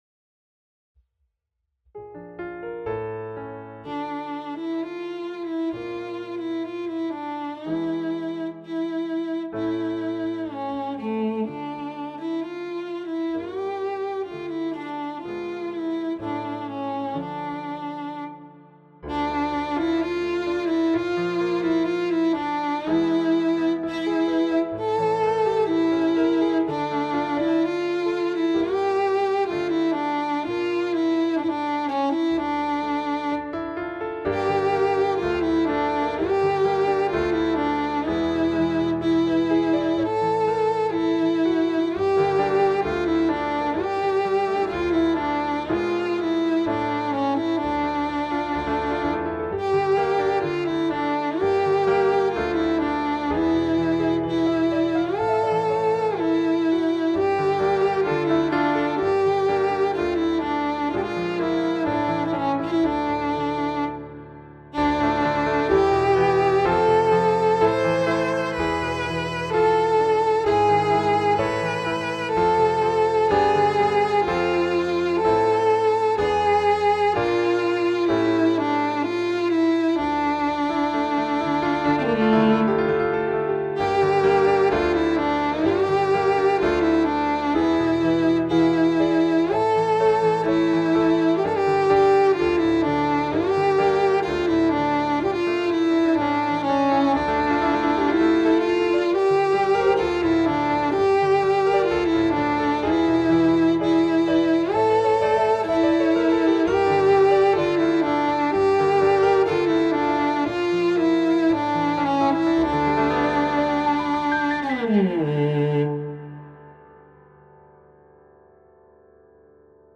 מאד ראשוני וספונטני
יש גם כמה בעיות קצב, אבל טייק אחד וזה בפורום